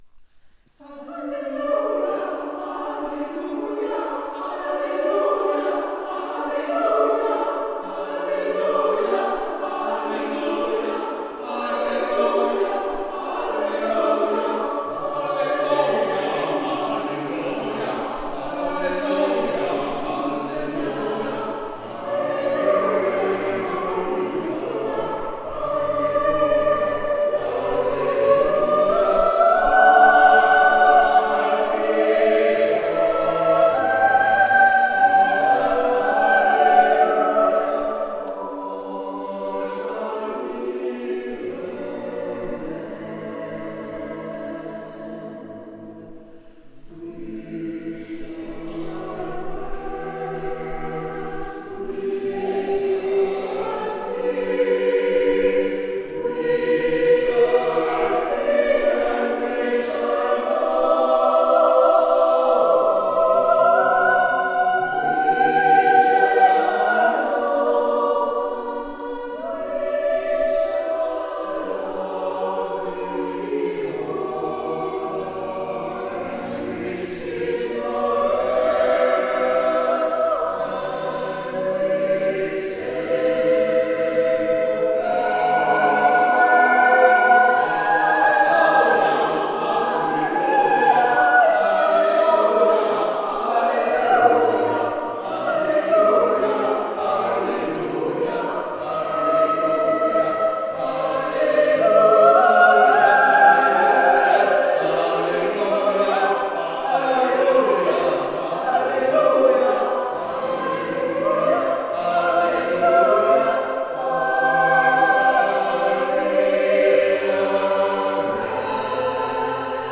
Musique chorale américaine
Extraits de la restitution de fin de stage dans l'église de Sainte Thumette à Penmarc'h, le 3 mai 2024